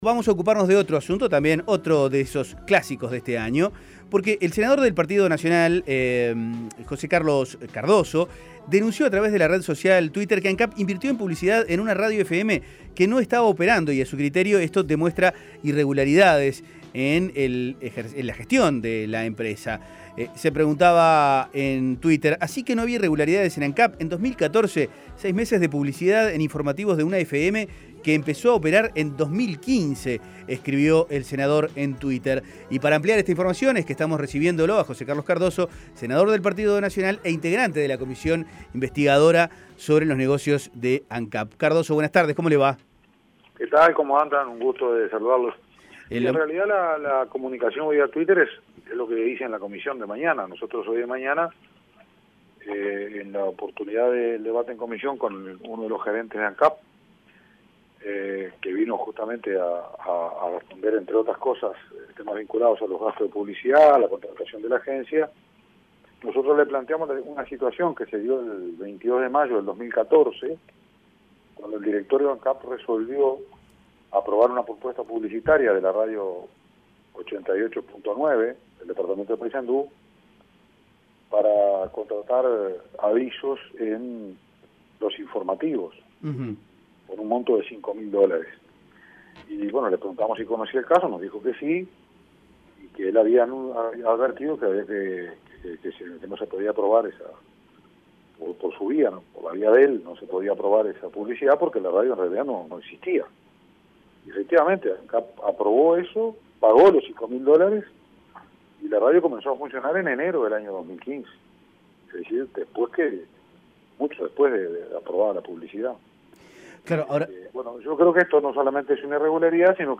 Escuche la entrevista en 810 Vivo